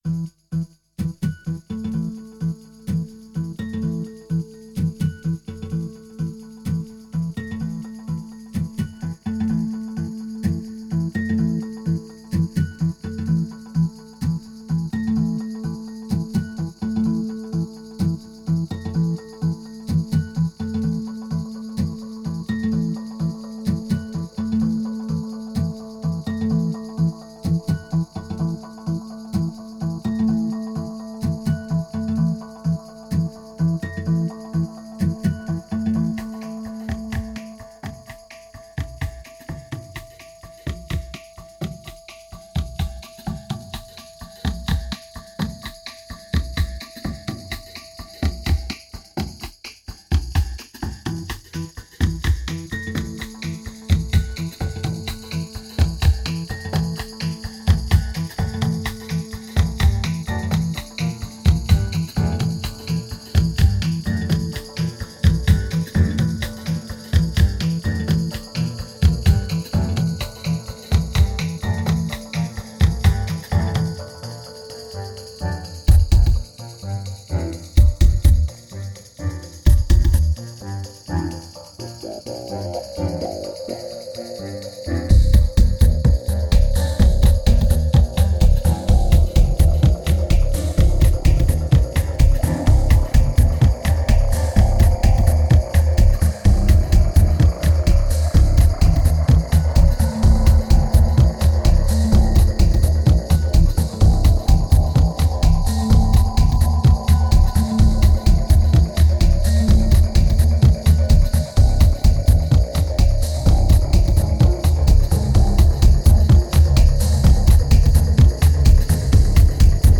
2248📈 - -42%🤔 - 127BPM🔊 - 2010-10-17📅 - -295🌟